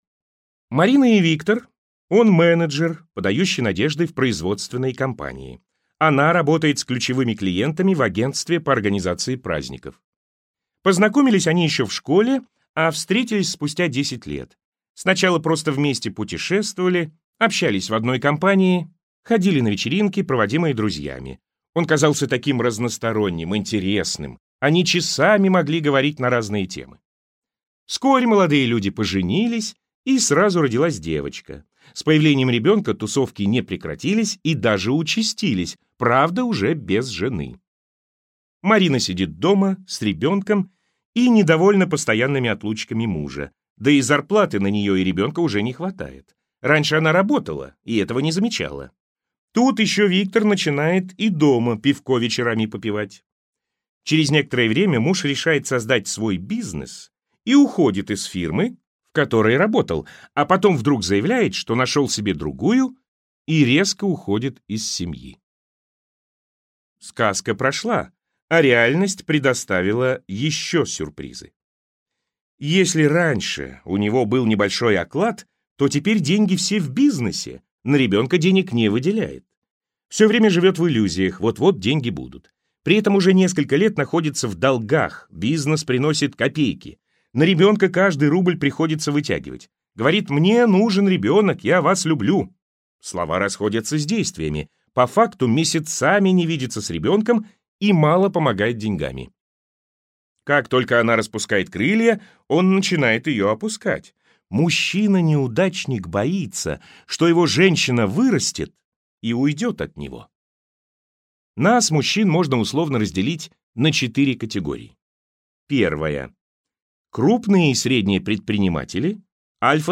Аудиокнига На самом деле я умная, но живу как дура!